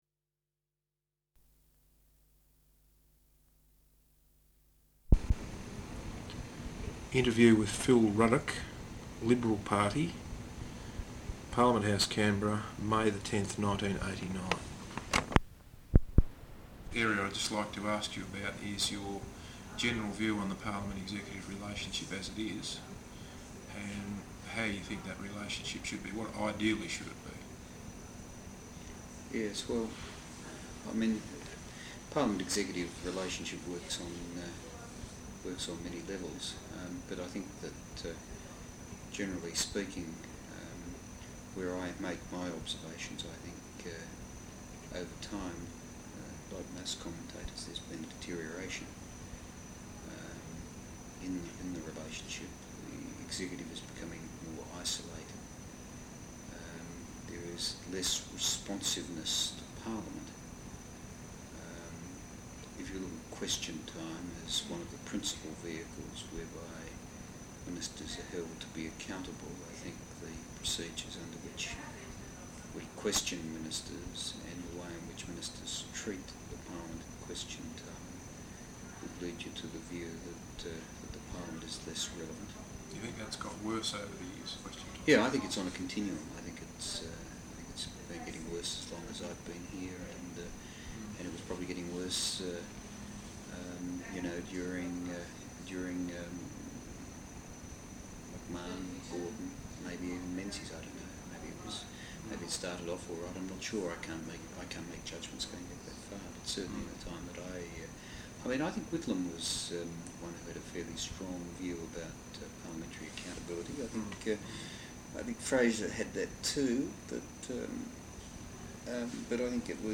Interview with Phillip Ruddock, Liberal Party, Parliament House Canberra, May 10th 1989.